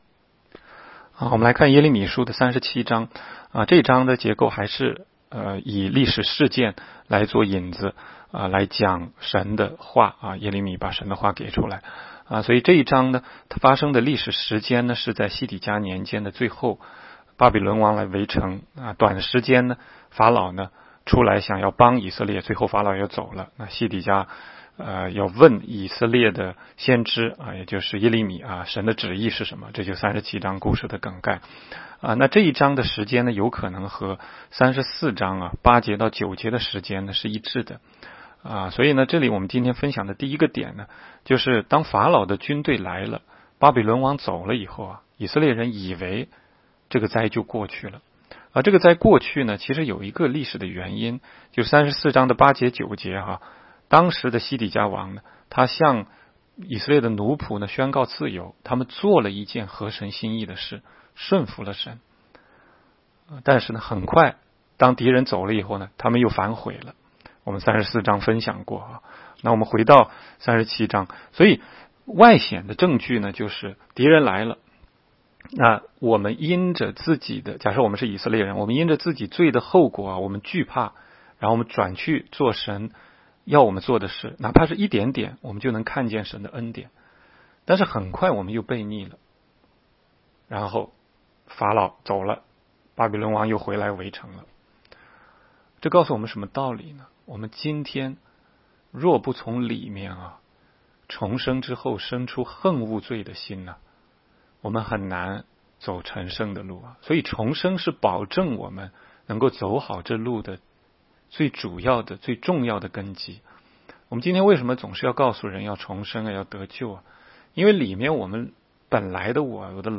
16街讲道录音 - 每日读经 -《耶利米书》37章